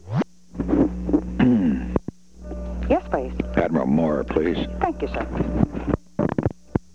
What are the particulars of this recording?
Location: White House Telephone